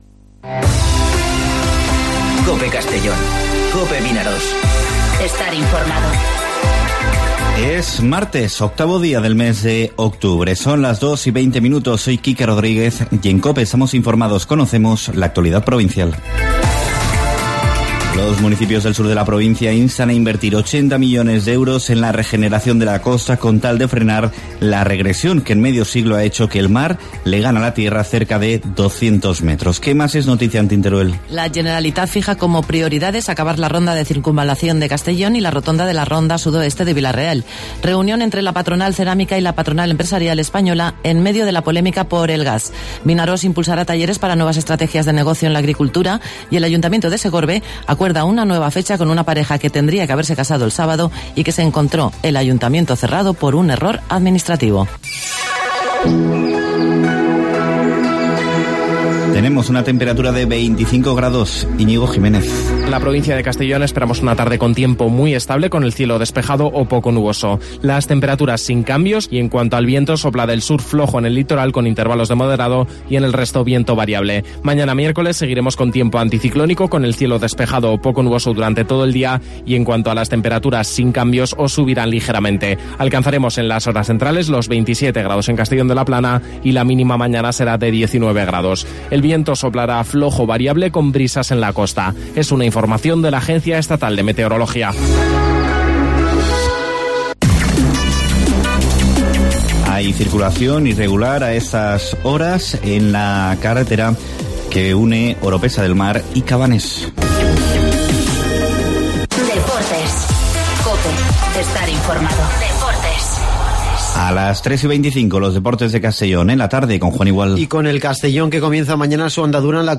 Informativo Mediodía COPE en Castellón (08/10/2019)